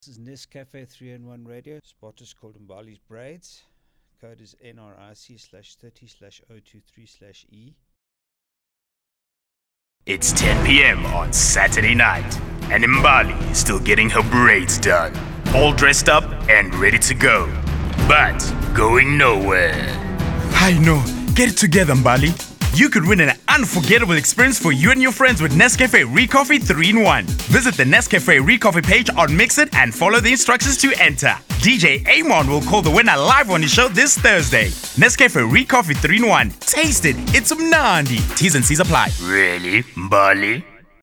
Vibrant voice artist,versitile,outspoken,south african voice,bright colourful voice,strong radio voice
Sprechprobe: Industrie (Muttersprache):
South African bright young male voice